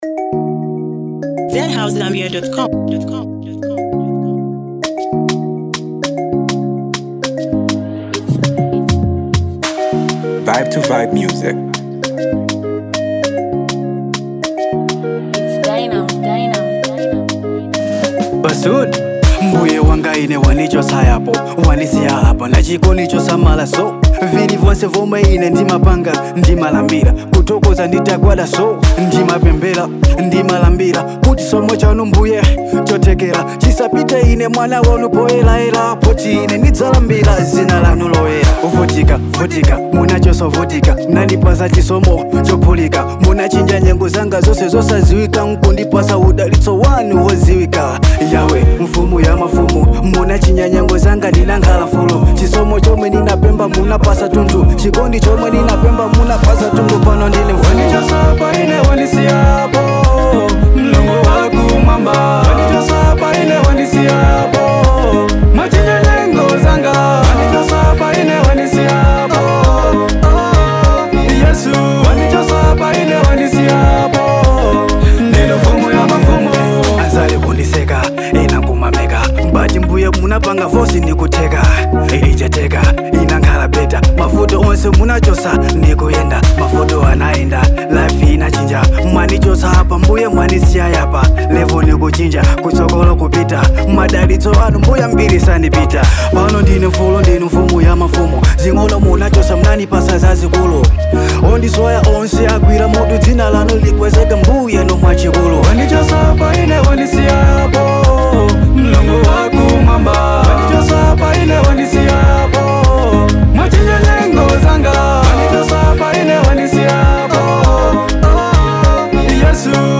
It’s raw, relatable, and powerful